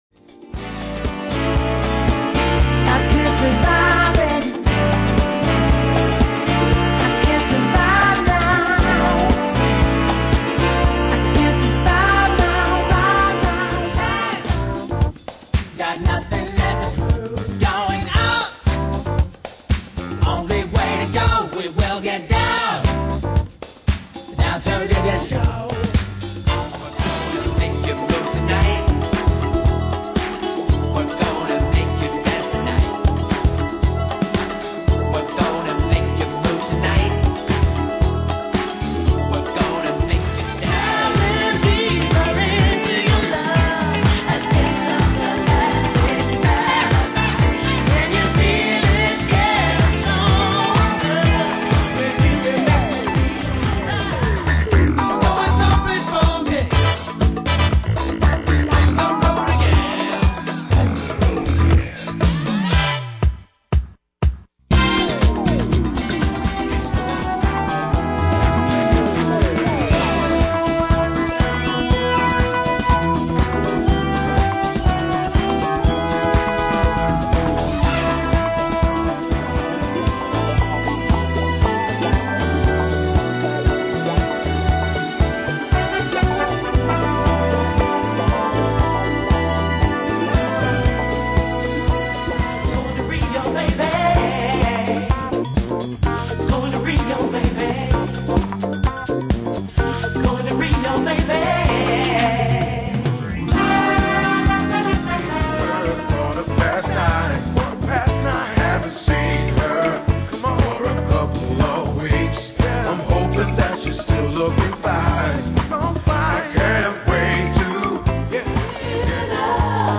Check out this collection of disco beats sure to make